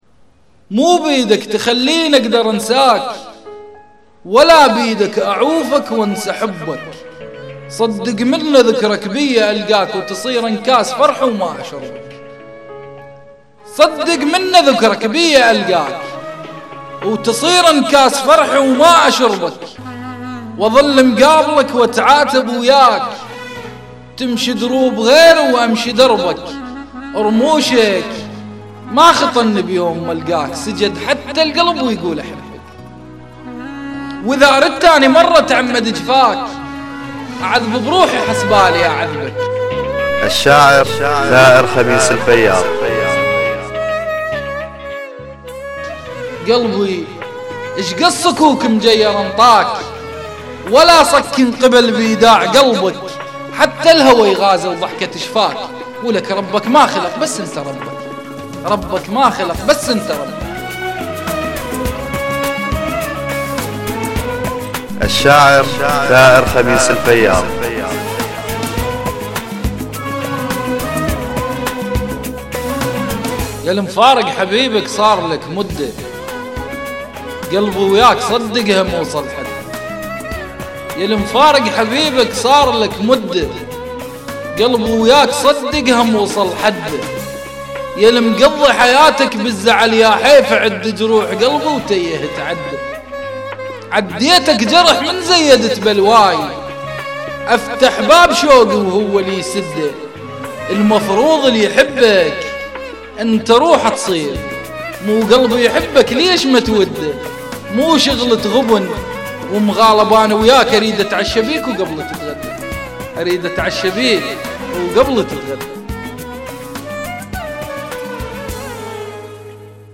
والالقاء خرافي